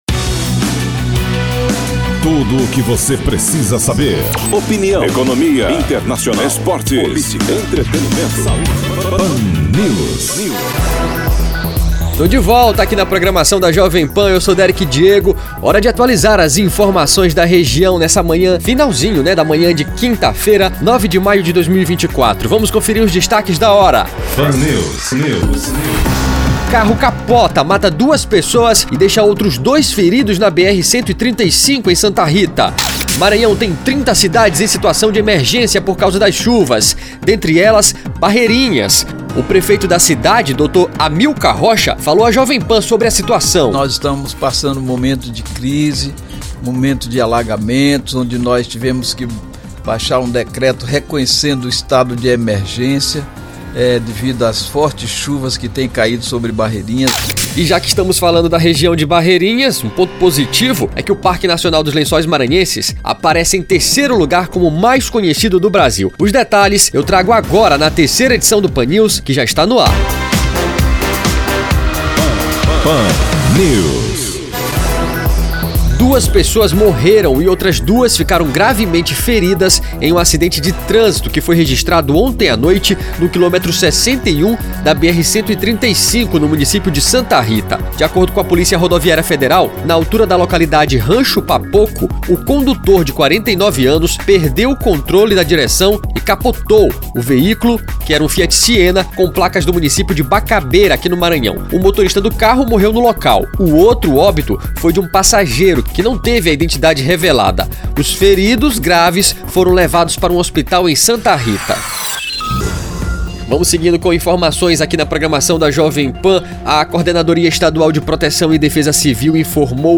O prefeito da cidade, Dr. Amilcar Rocha, falou à Jovem Pan sobre a situação da cidade ( Saiba mais aqui)